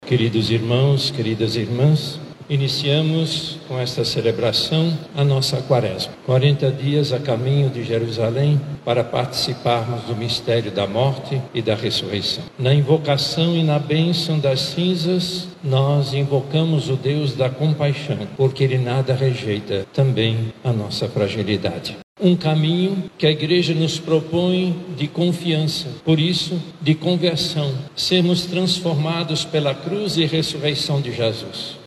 Em Manaus, a cerimônia aconteceu na Catedral Metropolitana, presidida pelo Cardeal Leonardo Steiner, e contou com a presença de centenas de fiéis.
Durante a homilia, o Cardeal Leonardo Steiner enfatizou os passos que os fiéis devem seguir nesse caminho de conversão. Ele lembrou que a Quaresma não é apenas um período de sacrifício, mas uma oportunidade para fortalecer a relação com Deus, buscando uma transformação interior verdadeira.